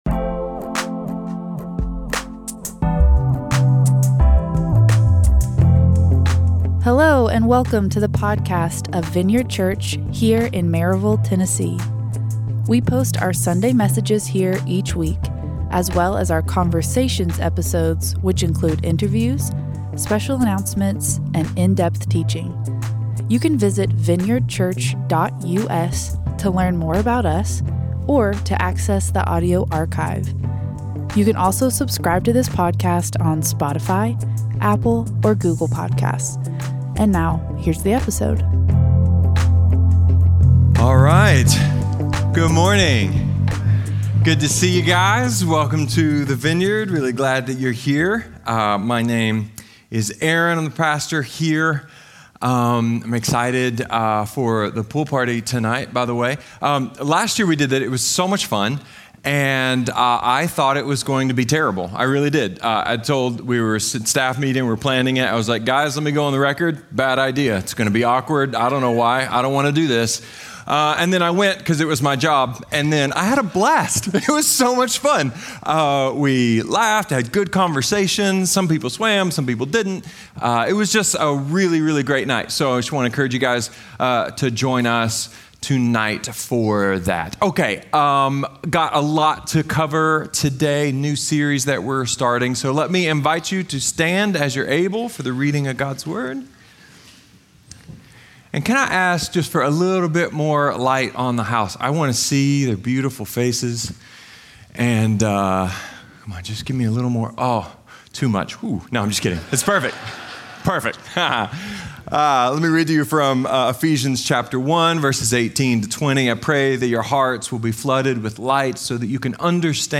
A sermon about how other cultures view the world, how our culture is shifting, and how the timeless gospel applies to all of it!